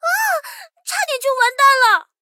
SU-76小破语音1.OGG